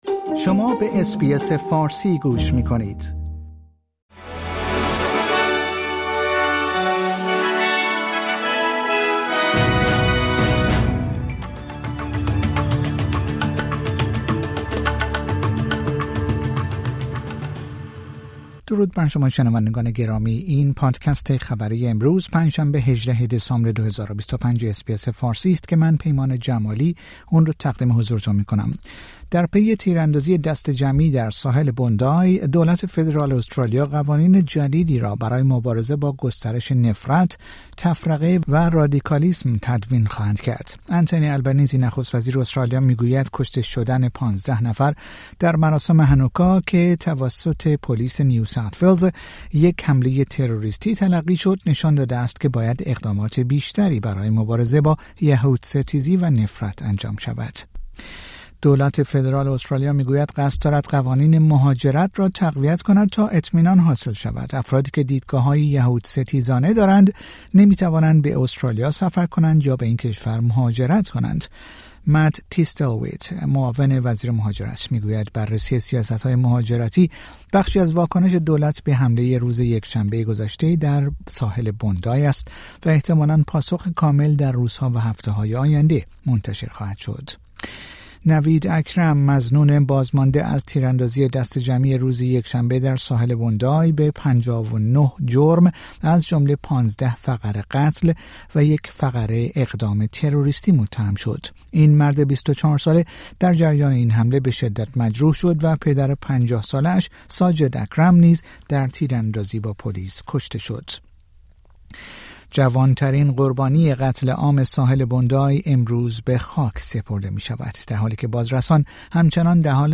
در این پادکست خبری مهمترین اخبار روز پنج شنبه ۱۸ دسامبر ارائه شده است.